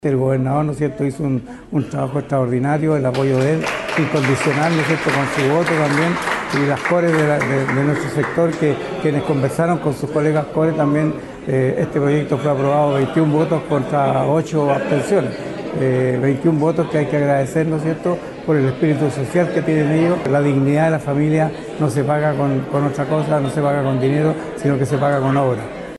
Al momento de la aprobación -21 votos a favor y 8 abstenciones-estuvo presente en la sala el Alcalde Juan Galdames Carmona, quien agradeció a los consejeros regionales por el respaldo al proyecto.